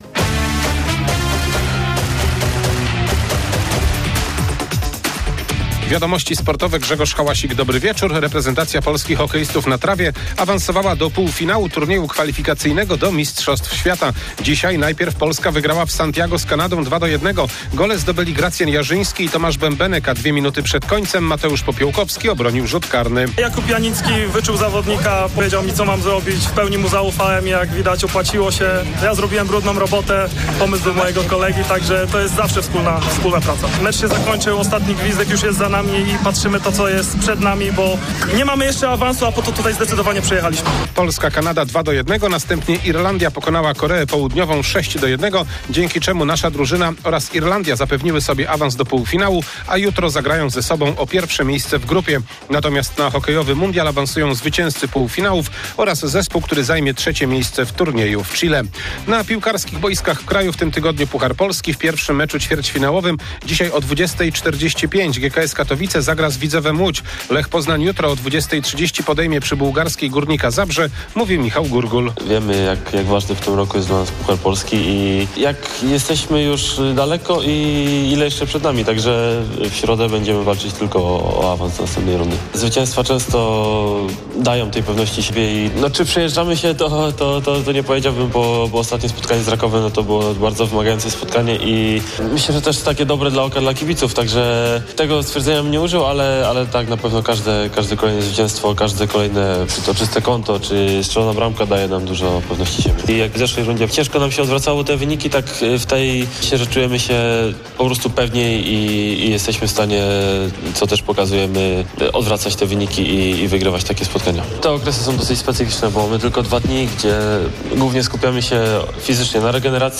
03.03.2026 SERWIS SPORTOWY GODZ. 19:05